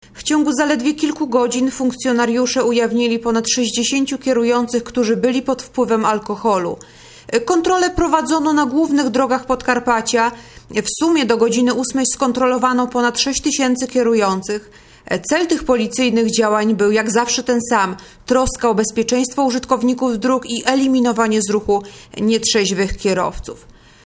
Mówi podkomisarz